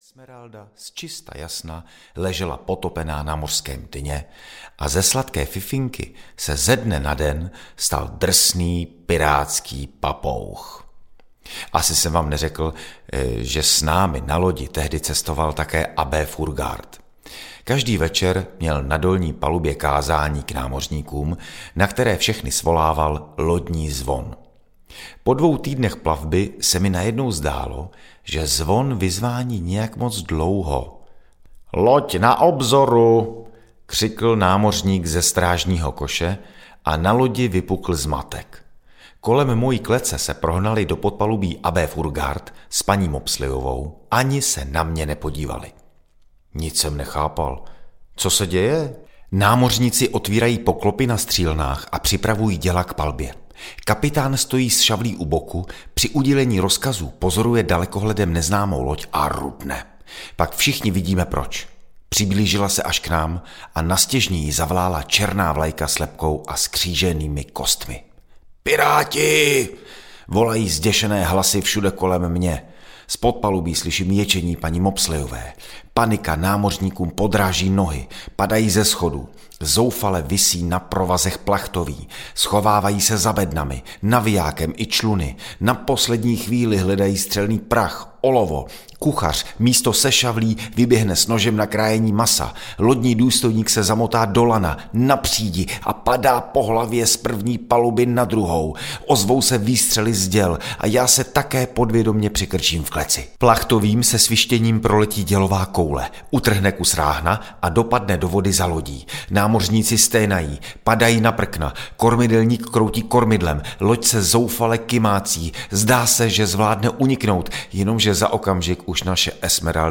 Ptákoviny pirátského papouška audiokniha
Ukázka z knihy
Užili jsme si s kapitánem Dinem, Perlořitkou, Spáleným Pepém, Drobečkem, Sardiňákem, Fajfkou Benem a ostatními piráty spoustu legrace, párkrát nám šlo o zobák, ale jedno vám povím – nikdy jsem se s nimi nenudil.Ostatně – poslechněte si sami, co všechno nás potkalo!Audiokniha obsahuje i 12 pirátských písniček od kapely Gentlemani!
• InterpretDalibor Gondík